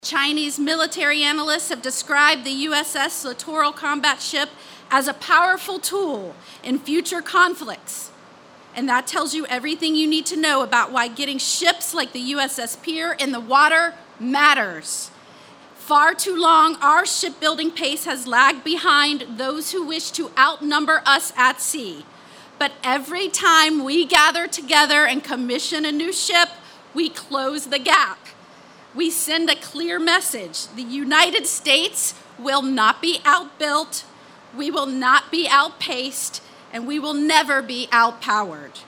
PANAMA CITY, F.L.(KCCR)- The U-S-S Pierre officially joined the fleet of the United States Navy Saturday morning with a traditional Commissioning Ceremony at Port Panama City Florida.
Littoral Combat Ship number 38 is getting noticed by other nations according to Florida Senator Ashley Moore.